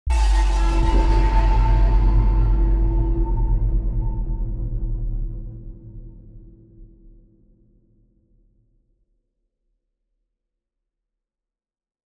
Scary Logo Sound Effect Download: Instant Soundboard Button